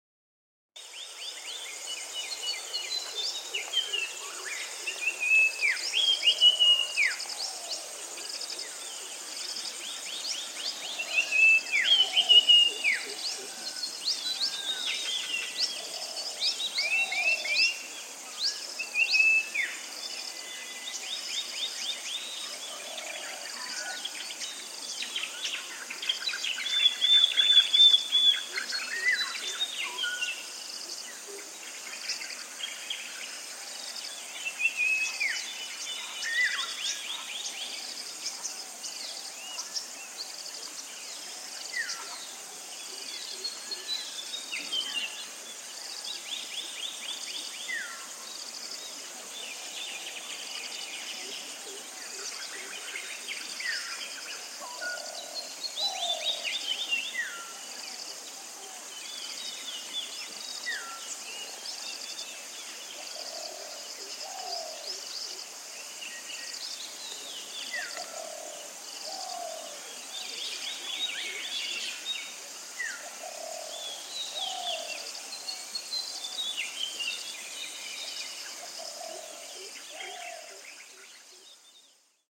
This page lists the animal species which are heard in the foreground or the background in the soundscapes featured on the map.
Yala National Park, Block III: recorded at 8.30 am on 05 October 2000
Insects, Black-headed Cuckoo-shrike, Common Myna, White-browed Fantail, Greater Coucal, Black-hooded Oriole, Common Iora, Western Spotted Dove, Sri Lanka Junglefowl, Indian Peafowl, Purple Sunbird, White-browed Bulbul and Indian Pitta.
Yala-Block-III-Galge-Oct.2000_MP3.mp3